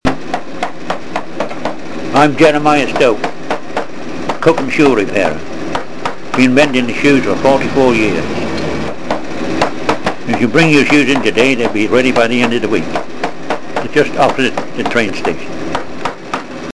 Cookham cobblers.mp3